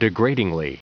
Prononciation du mot degradingly en anglais (fichier audio)
Prononciation du mot : degradingly